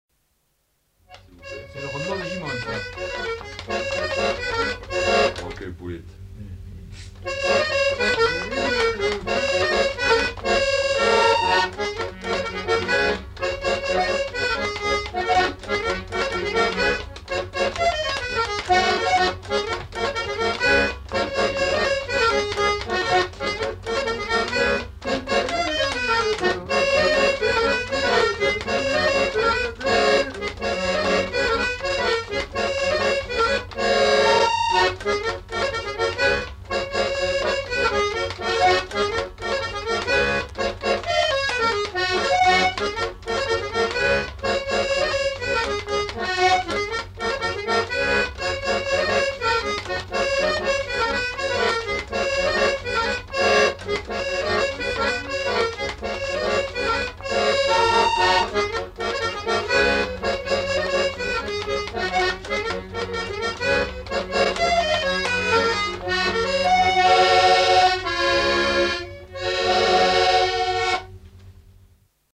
Rondeau
Aire culturelle : Savès
Lieu : Polastron
Genre : morceau instrumental
Instrument de musique : accordéon diatonique
Danse : rondeau